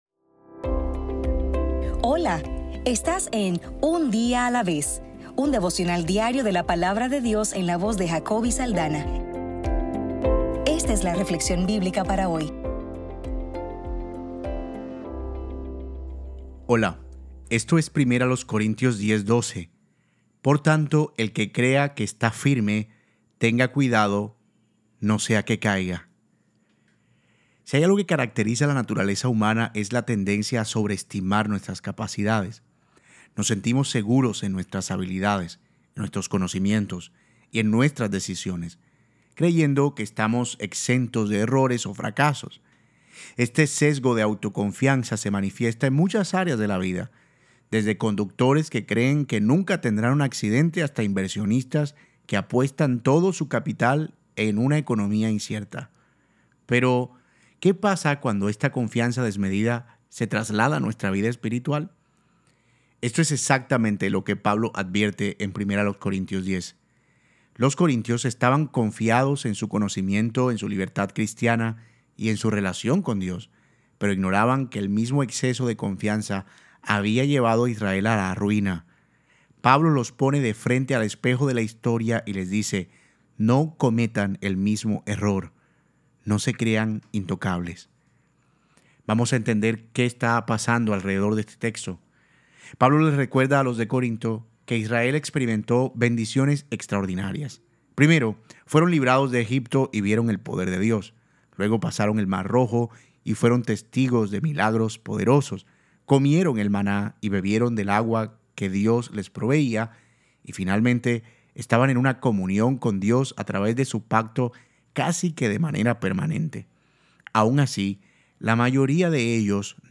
Devocional para el 23 de febrero